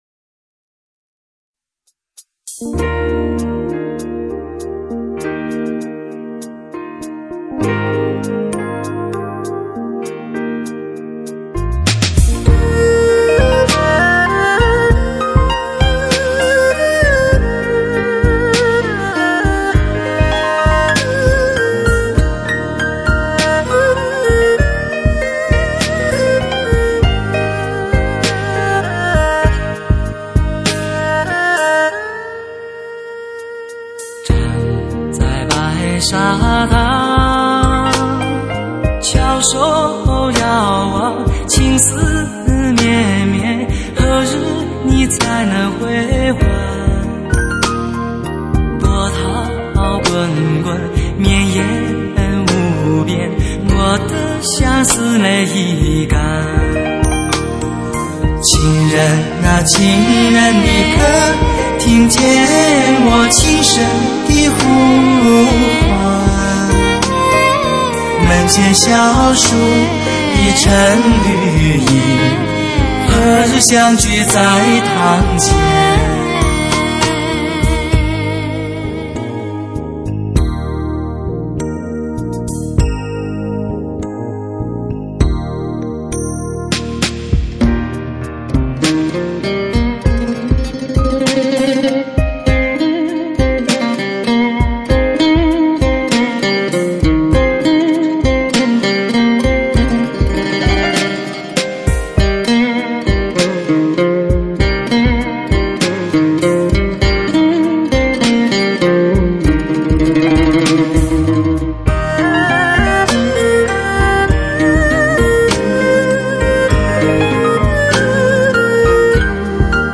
独具一格的表演形式，东方传统乐器与现代音乐的融合。即兴的钢琴演奏与中国民族乐器的对话，刚柔相济、幽婉、激昂。